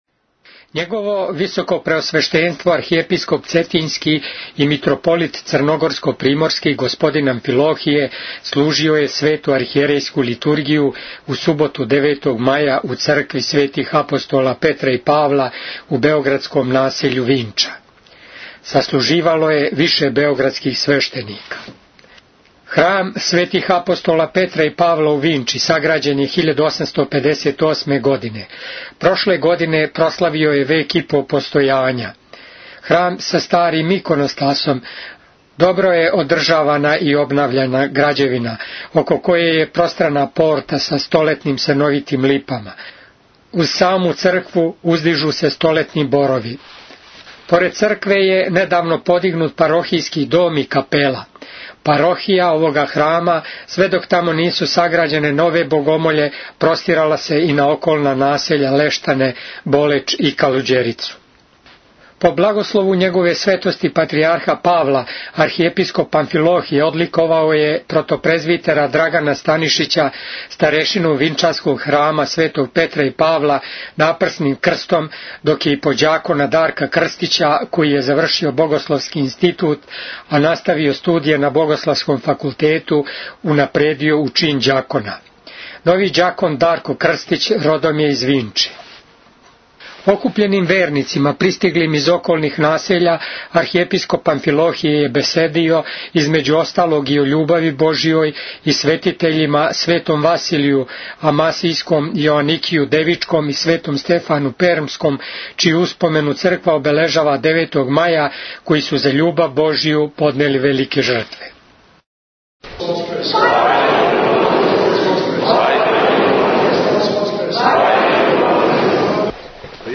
Бесједа Архиепископа Г. Амфилохија у цркви Светих Апостола Петра и Павла у београдском насељу Винча, 9 мај 2009 | Радио Светигора
Tagged: Бесједе